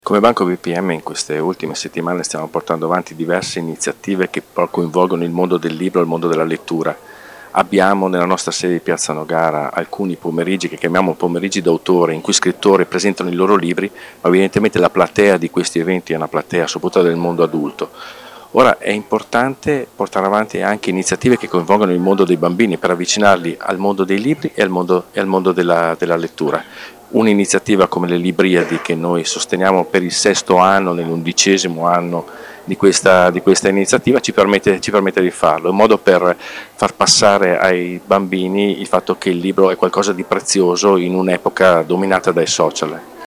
ha intervistato alcuni dei presenti alla conferenza stampa: